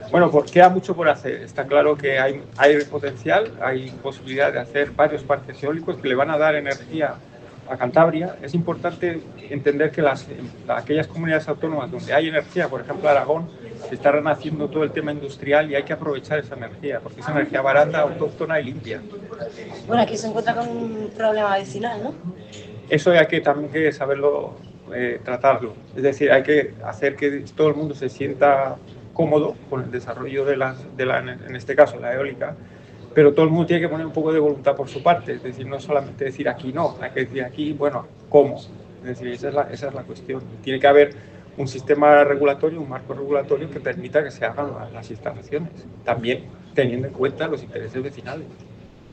Jornada Energía en Cantabria, situación actual y renovables - CEOE-CEPYME